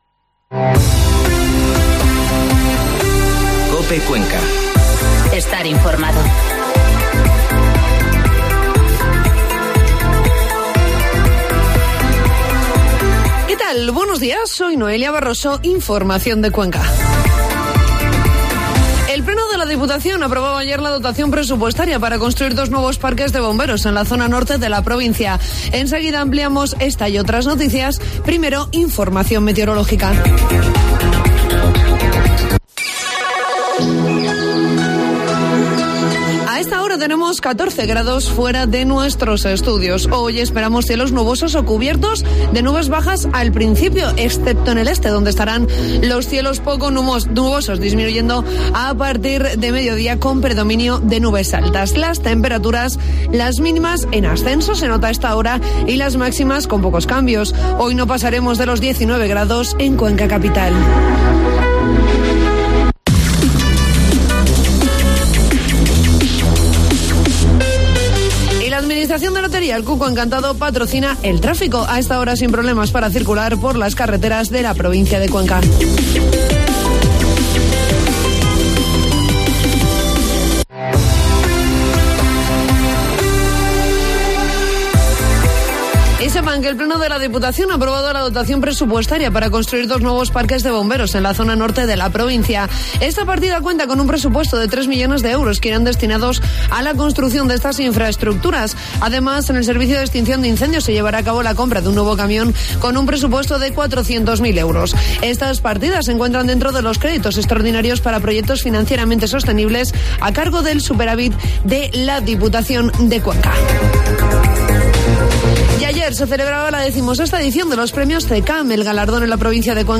Informativo matinal COPE Cuenca 31 de octubre